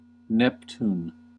PronunciationUS: /ˈnɛptn/
En-us-Neptune.ogg.mp3